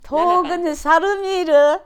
Aizu Dialect Database
Type: Yes/no question
Final intonation: Rising
Location: Showamura/昭和村
Sex: Female